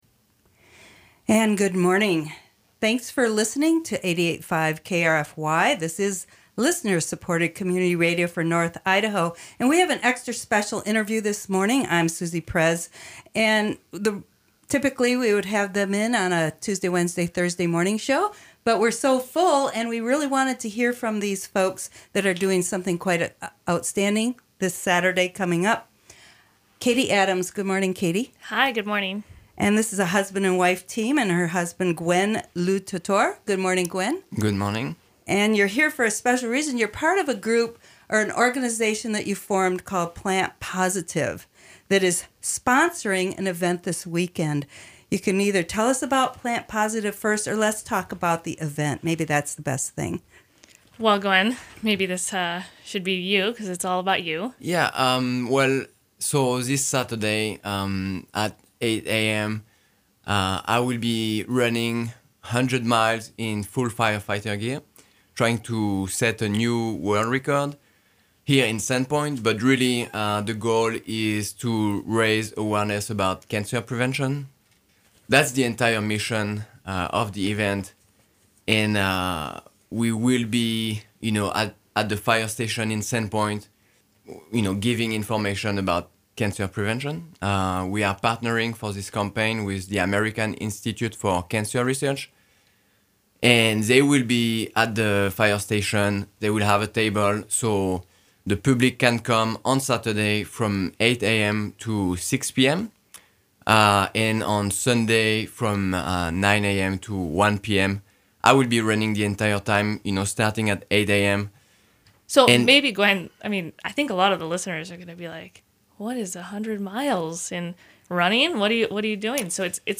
EXTRA Monday Interview: Monday, April 23, 2018 – Plant Positive 100 Mile Run for Cancer Prevention